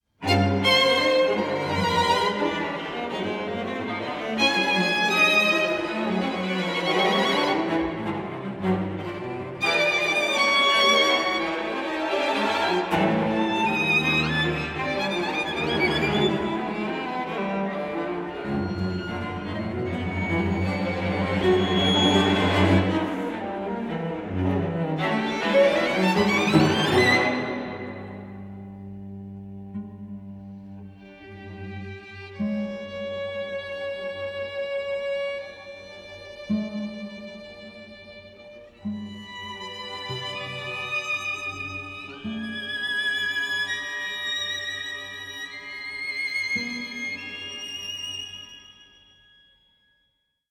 24-bit stereo